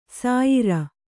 ♪ sāyira